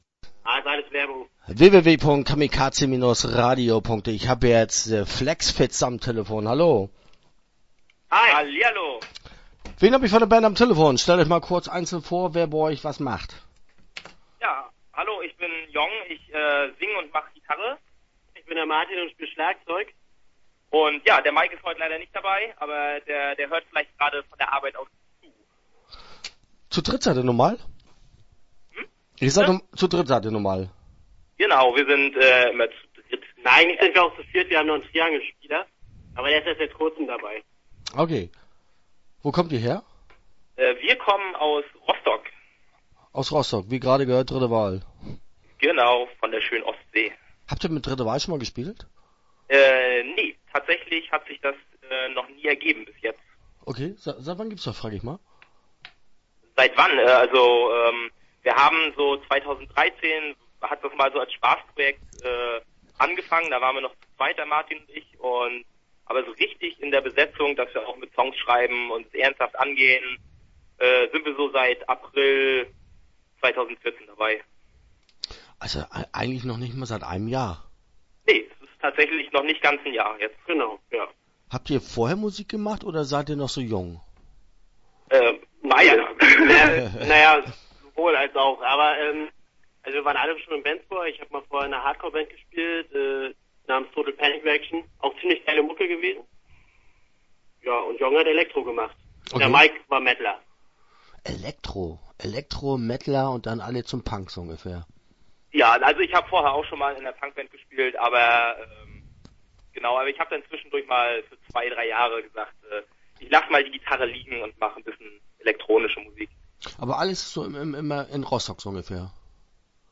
Start » Interviews » The FlexFitz